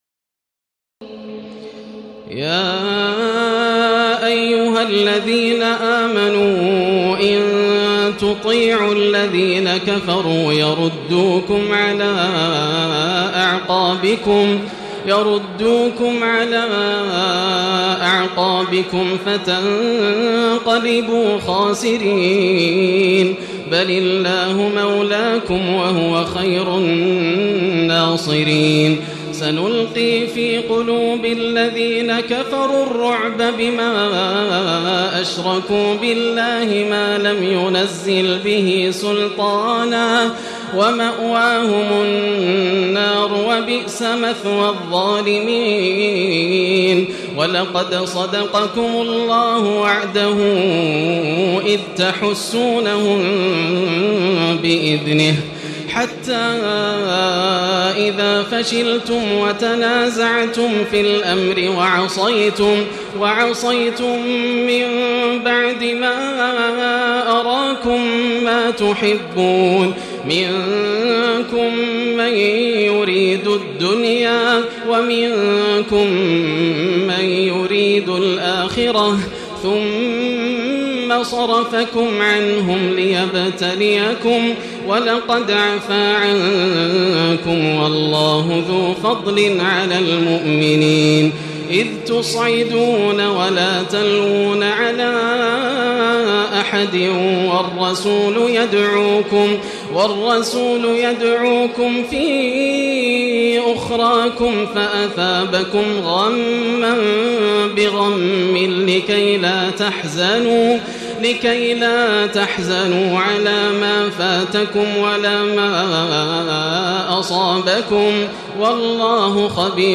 تراويح الليلة الرابعة رمضان 1436هـ من سورتي آل عمران (149-200) و النساء (1-18) Taraweeh 4 st night Ramadan 1436H from Surah Aal-i-Imraan and An-Nisaa > تراويح الحرم المكي عام 1436 🕋 > التراويح - تلاوات الحرمين